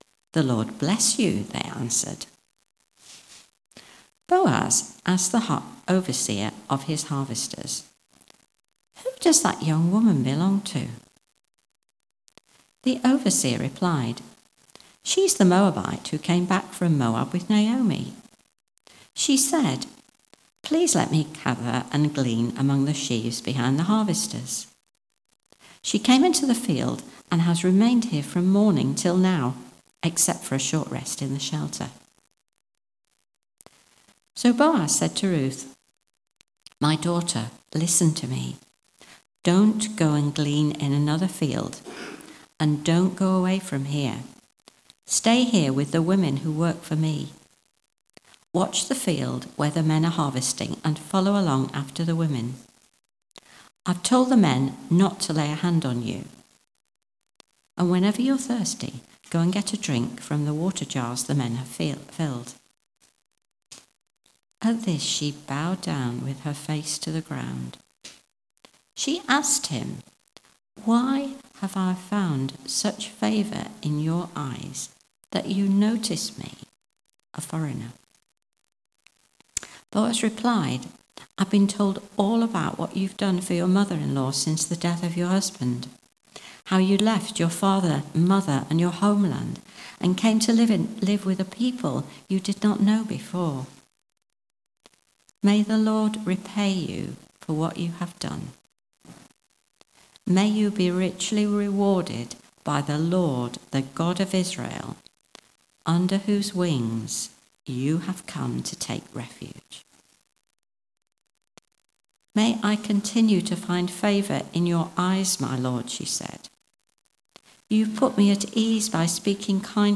Sorry, this starts during the reading.